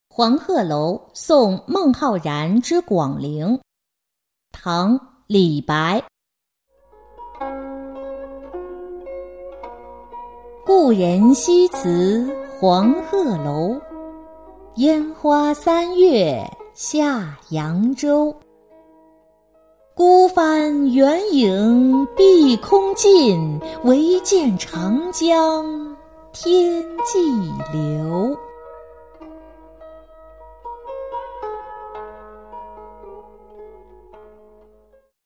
杂诗三首·其二-音频朗读